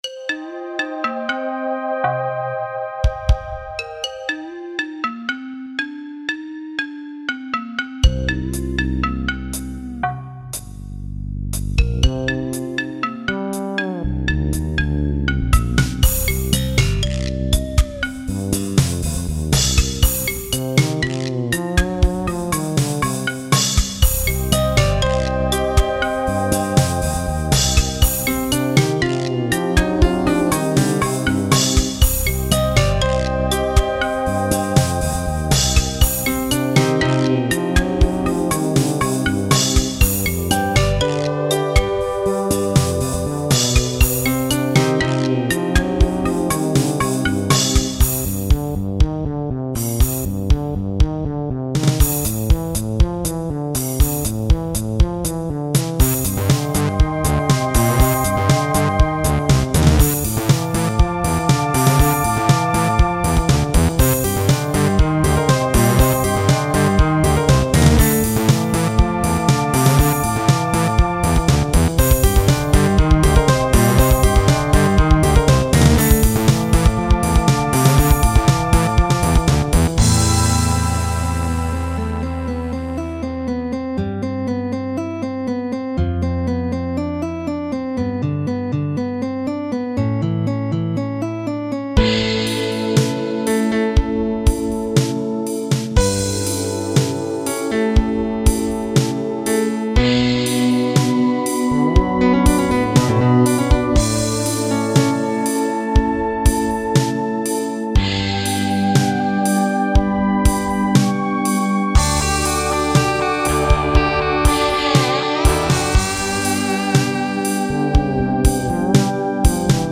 la mia prima canzone "digitale" in assoluto!